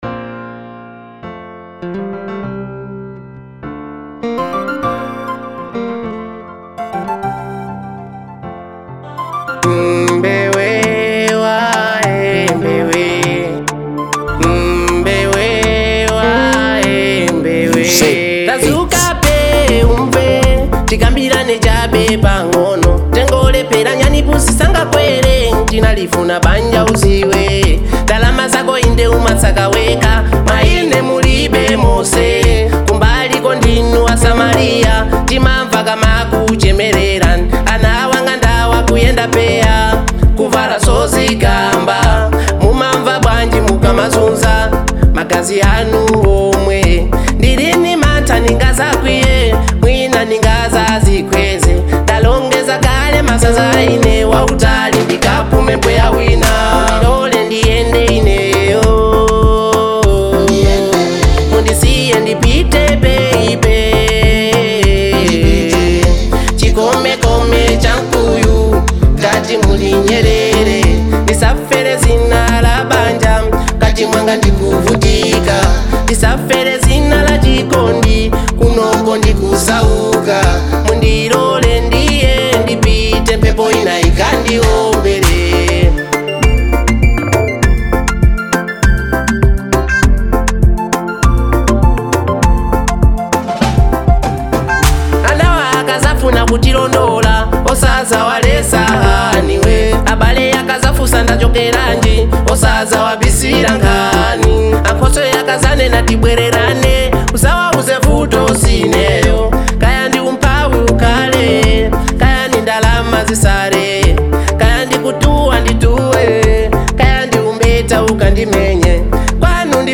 Malawian Afro • 2025-07-18